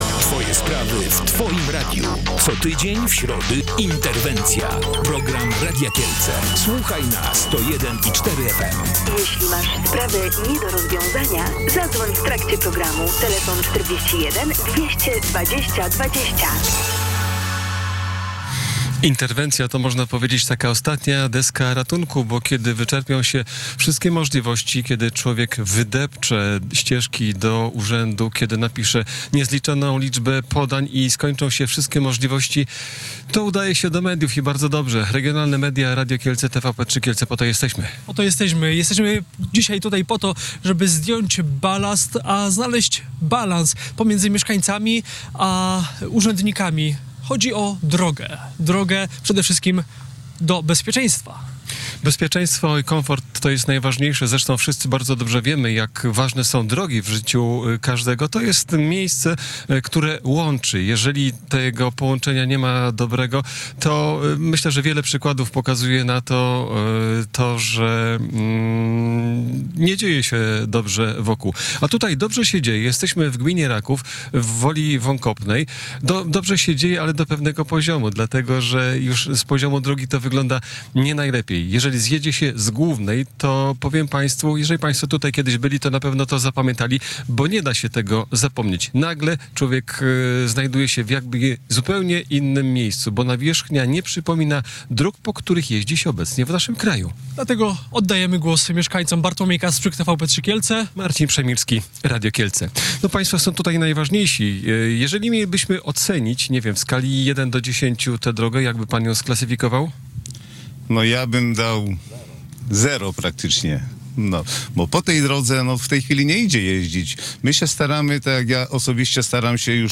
Interwencja Radia Kielce.